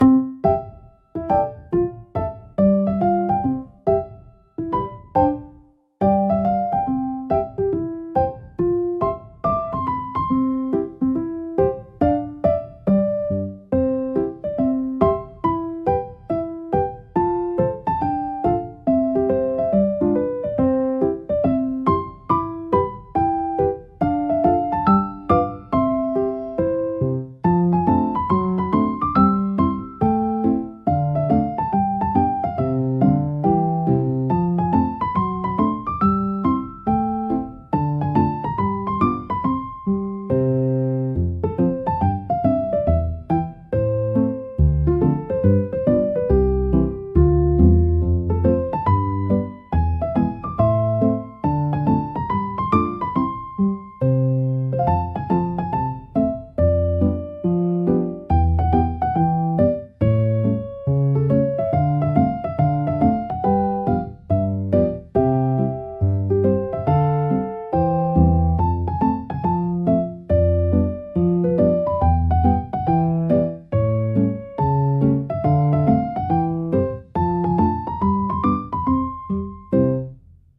シンプルなメロディラインが心地よいリズムを生み、穏やかながらも前向きなムードを演出します。
ピアノの柔らかなタッチが集中をサポートし、疲れを癒す効果を発揮します。